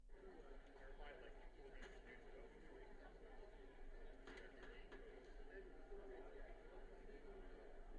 ハイブリッド型アクティブノイズキャンセリング
ハイブリッド型 ANC: オン
オフの状態でも遮音性が高いですが、ANCをオンにすることで大幅にカットできます。
razer-blackshark-v3-pro-hybrid-anc-on.wav